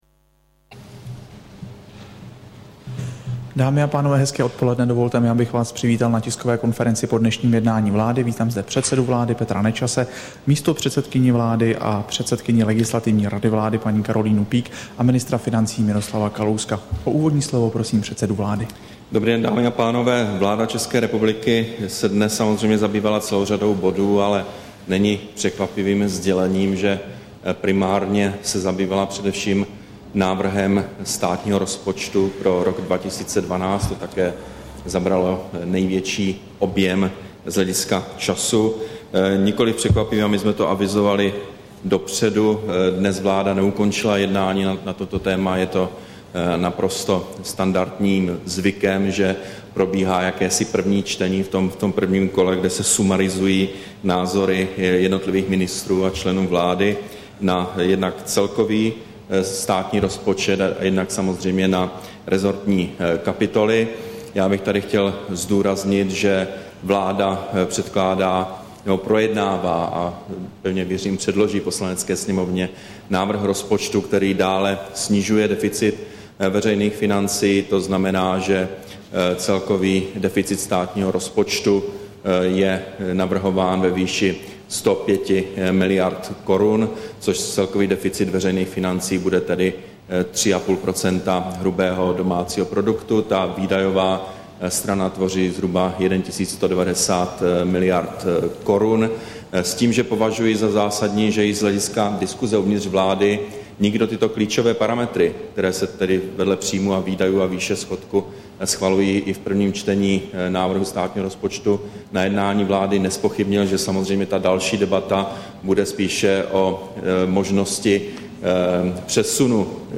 Tisková konference po jednání vlády, 8. září 2011